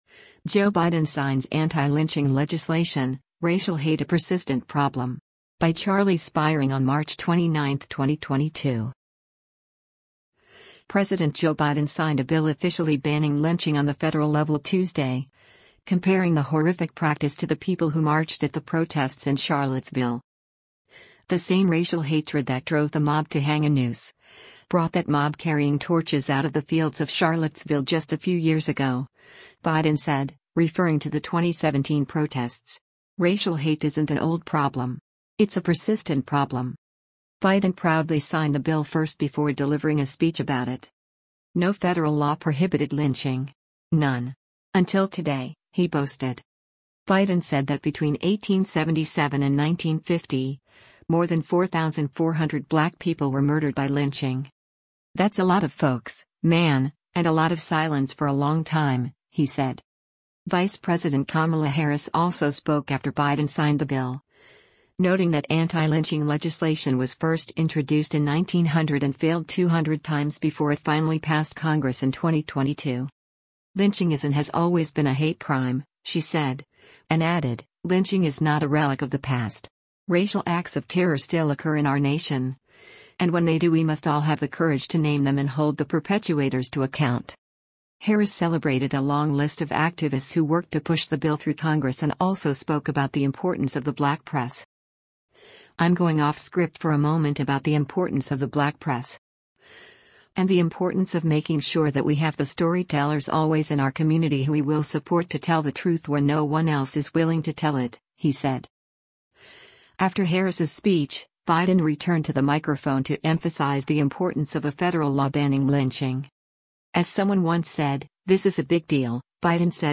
Biden proudly signed the bill first before delivering a speech about it.
After Harris’s speech, Biden returned to the microphone to emphasize the importance of a federal law banning lynching.